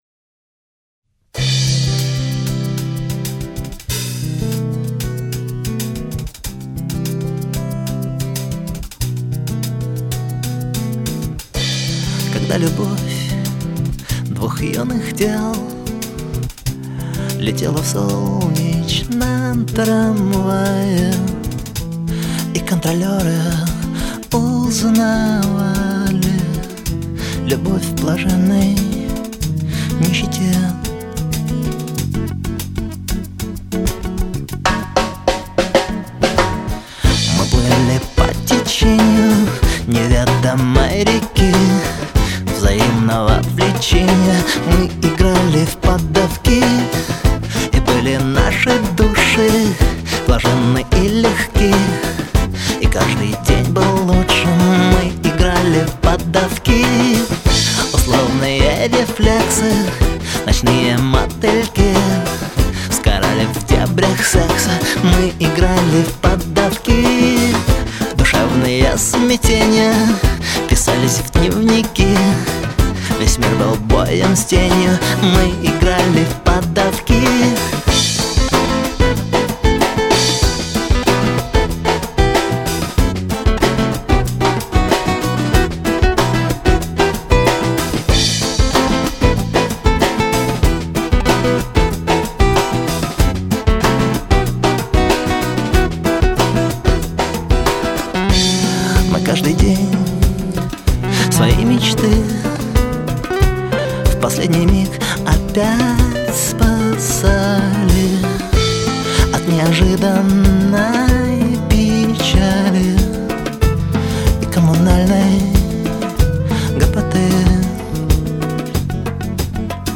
Поп рок
Софт рок